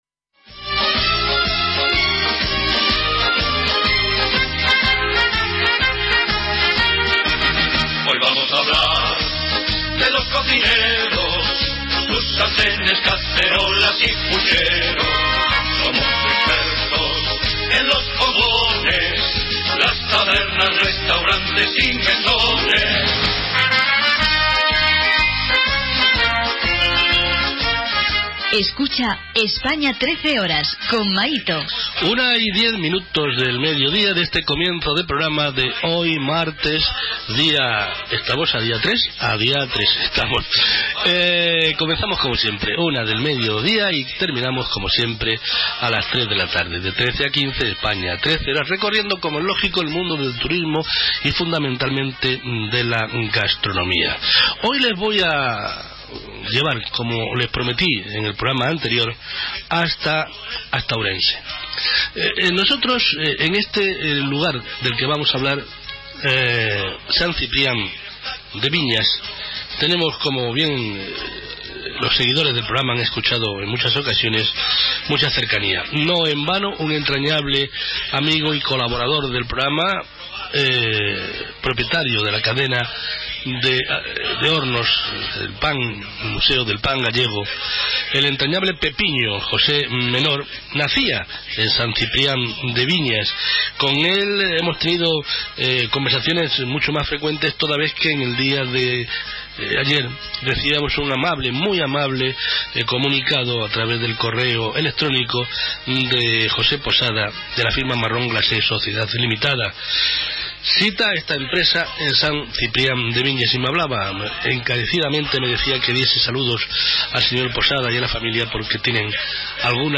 entrevista radiofónica